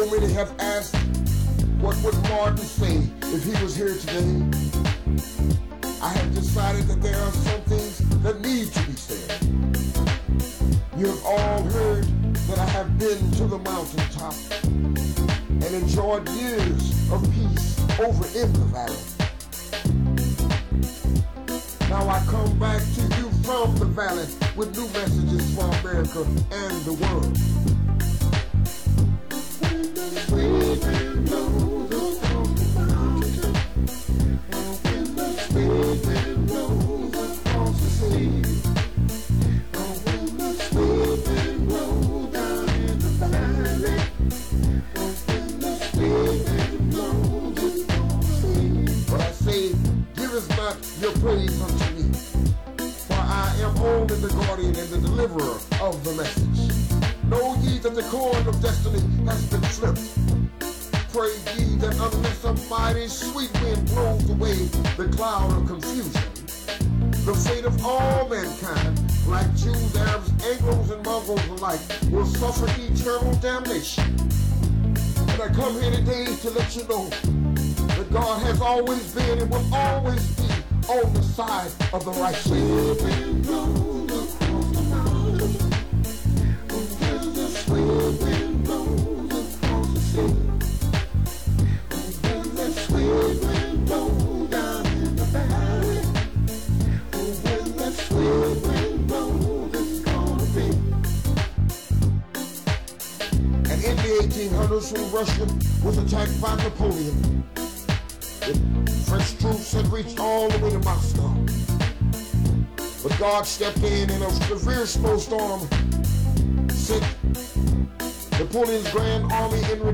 WHEN DAT SWEET WIND BLOWS I - SERMON AUDIO